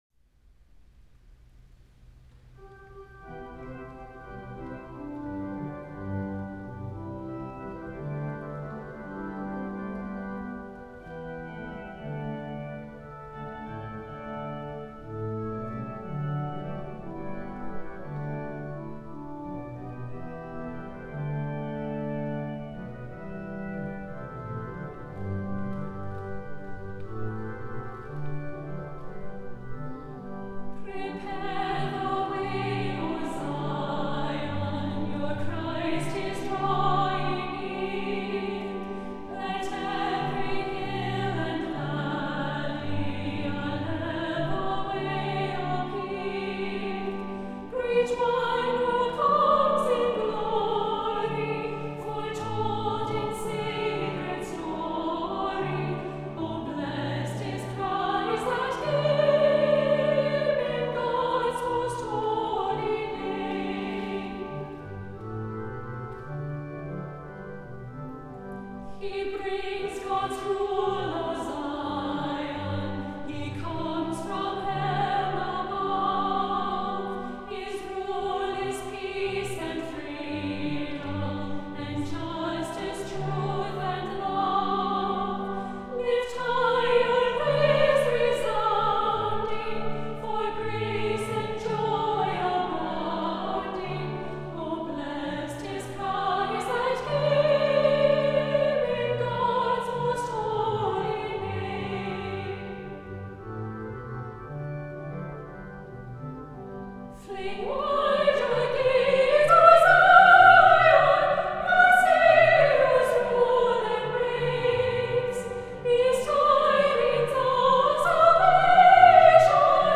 • Music Type: Choral
• Voicing: Treble Voices
• Accompaniment: Organ